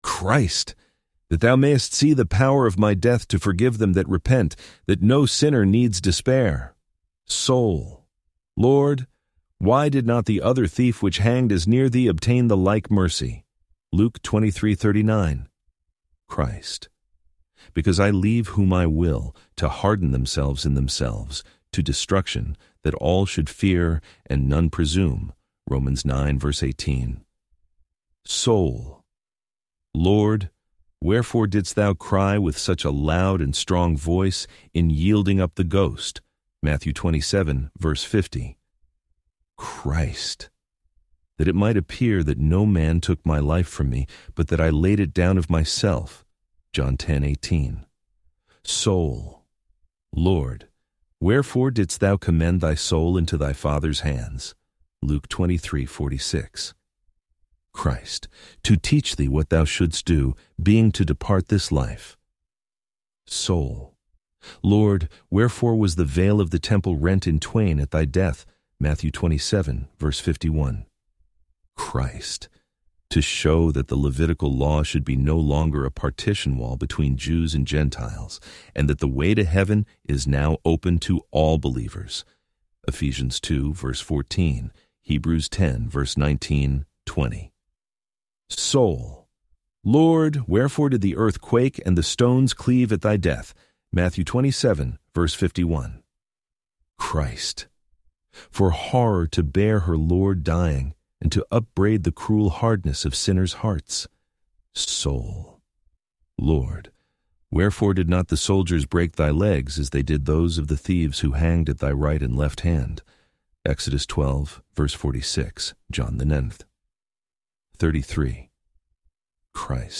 Complete Audiobook